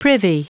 Prononciation du mot privy en anglais (fichier audio)
Prononciation du mot : privy